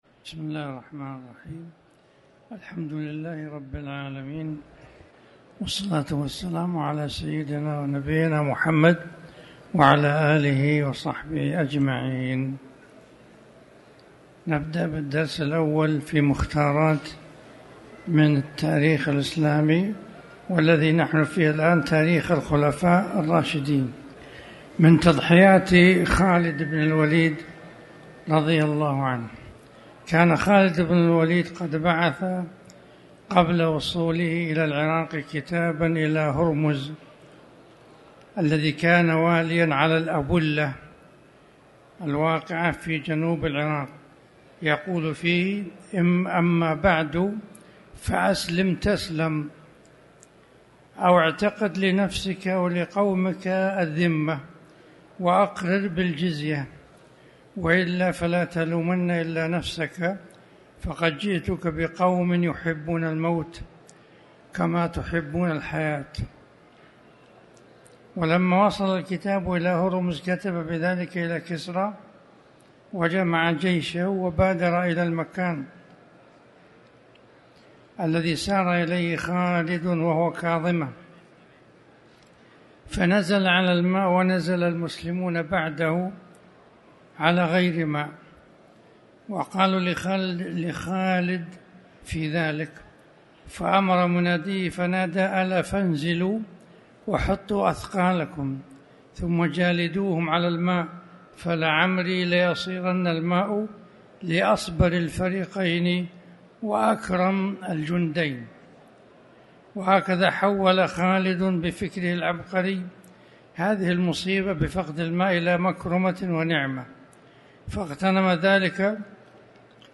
تاريخ النشر ٤ ذو القعدة ١٤٤٠ هـ المكان: المسجد الحرام الشيخ